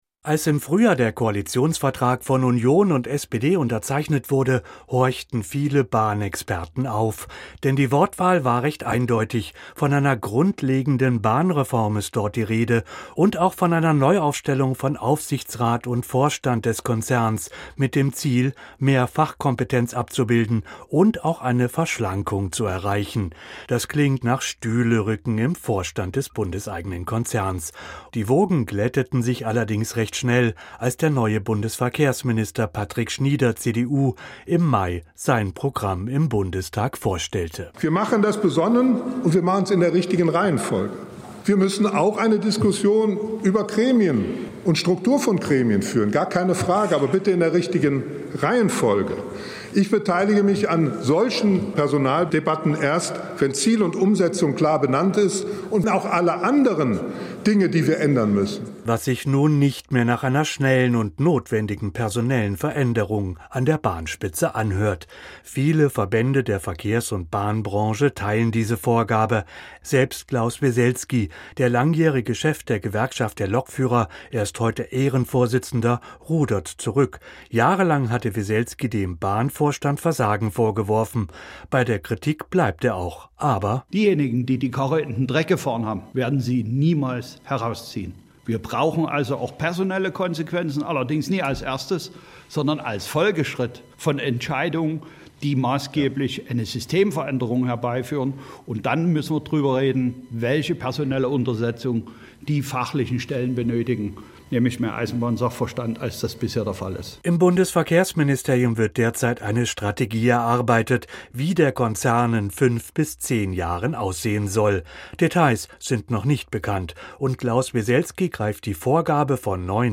Kommentar: Die Bahn gehört uns allen